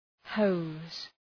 Προφορά
{həʋz}
hose.mp3